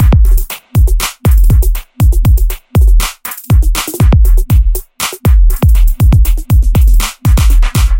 描述：调：G 速度：90bpm marchy类型的鼓线，伴随着夜里的碰撞循环
Tag: 120 bpm Chill Out Loops Drum Loops 1.35 MB wav Key : G